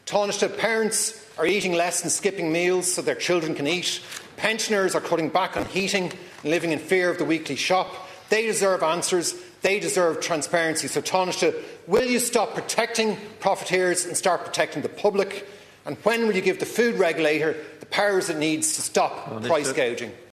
Deputy Cian O Callaghan’s calling on the Tanaiste to protect the public’s interest over rising prices: